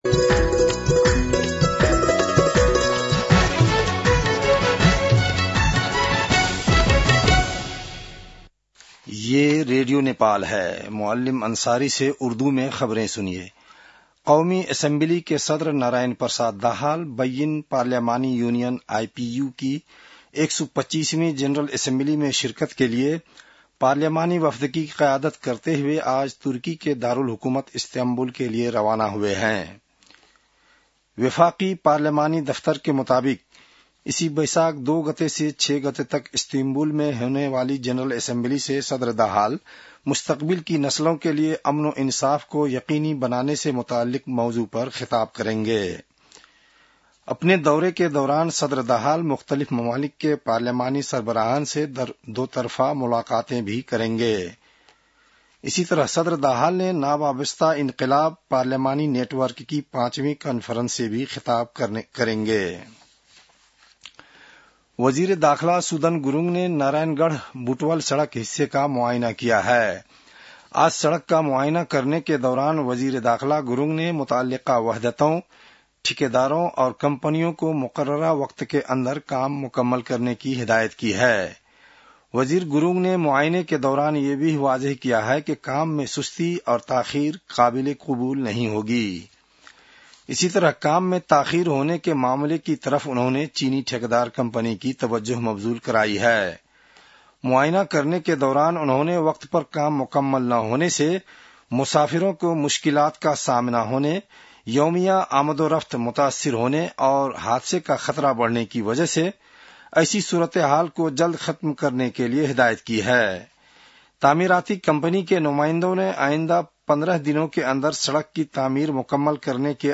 उर्दु भाषामा समाचार : १ वैशाख , २०८३